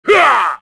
Jin-Vox_Attack1_kr.wav